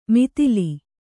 ♪ mitili